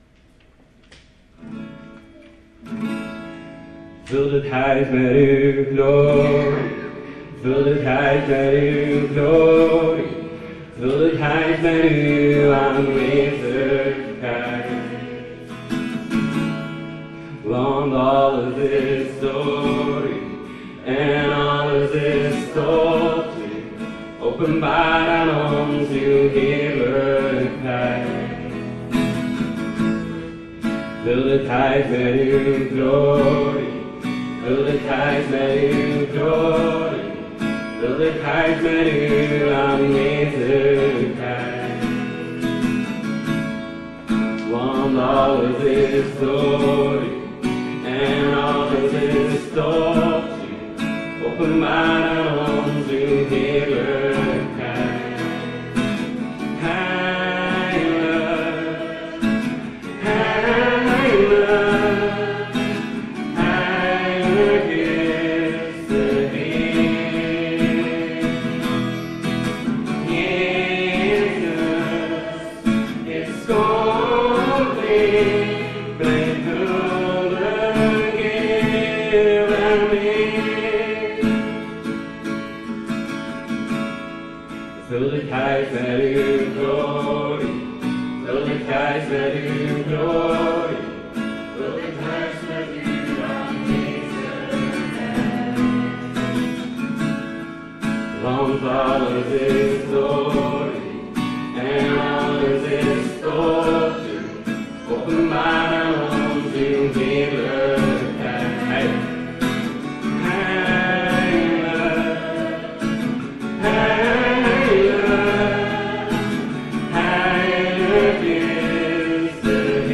De zoektocht naar geluk – Evangelische Kerk De Pottenbakker VZW